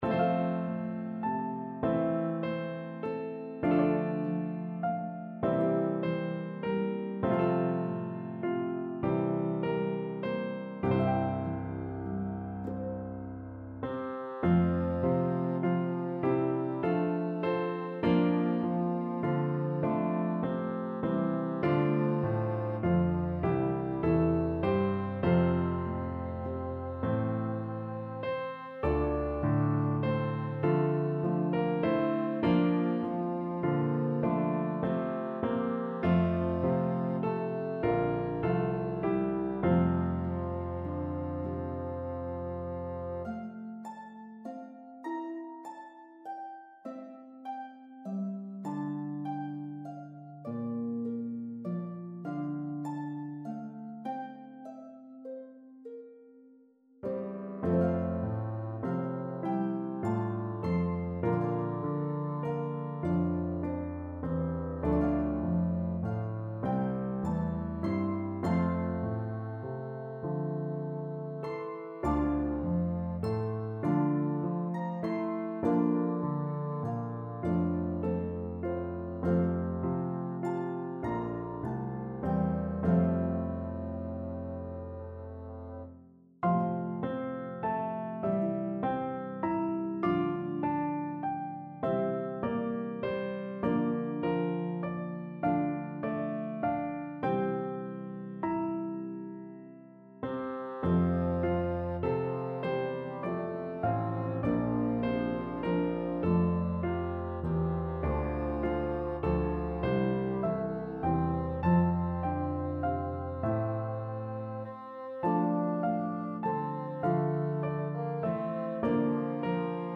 Harp, Piano, and Bassoon version